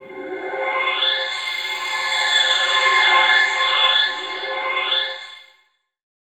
Index of /90_sSampleCDs/Chillout (ambient1&2)/09 Flutterings (pad)
Amb1n2_n_flutter_c.wav